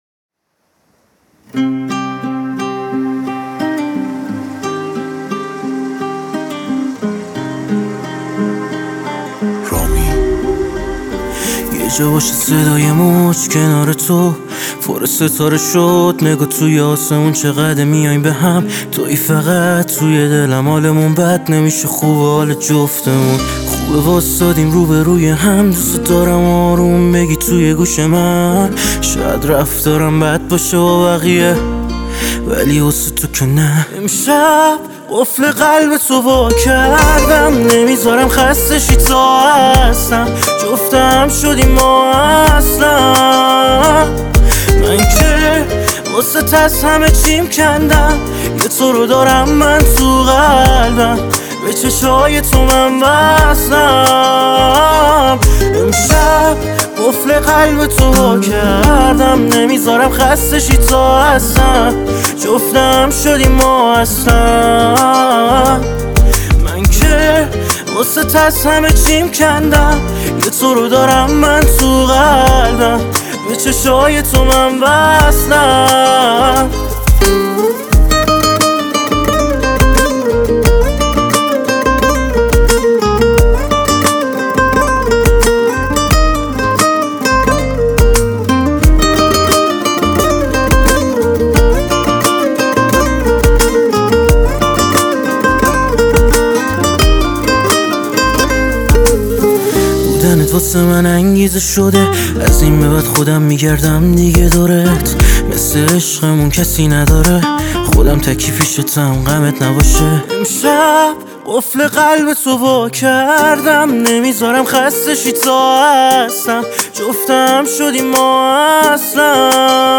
Slow Version